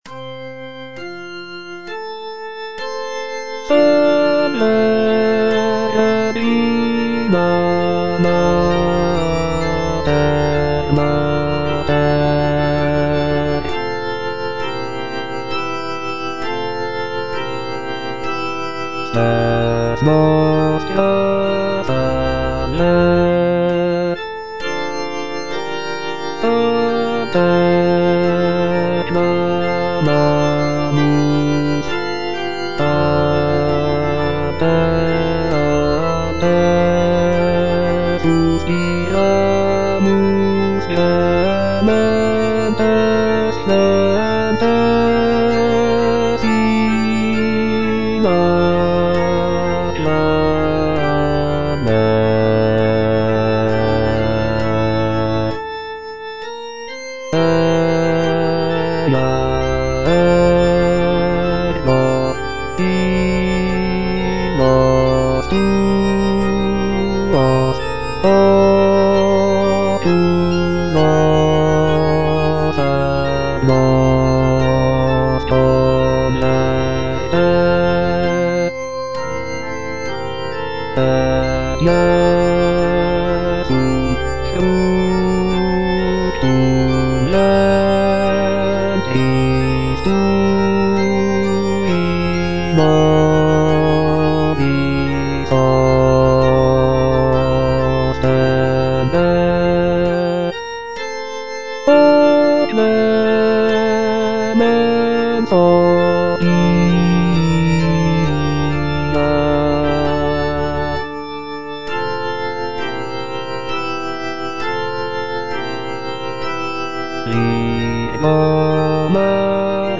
G. FAURÉ (ARR. H. BÜSSER) - SALVE REGINA Bass (Voice with metronome) Ads stop:
"Salve Regina" is a choral work composed by Gabriel Fauré, arranged by Henri Büsser. This piece is a setting of the traditional Latin hymn to the Virgin Mary, known for its hauntingly beautiful melodies and rich harmonies.